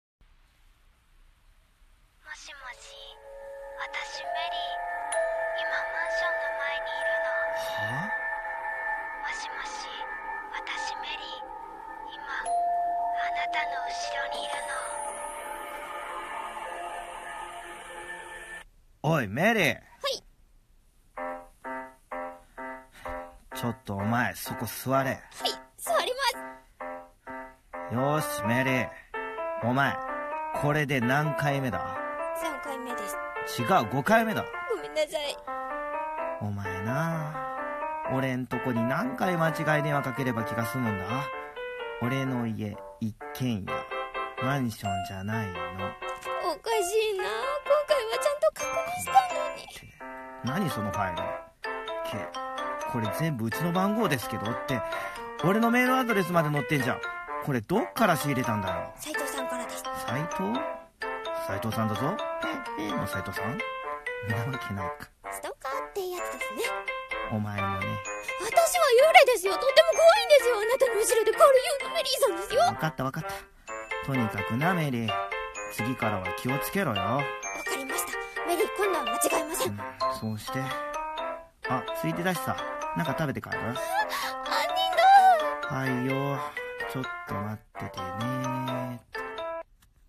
ギャグ声劇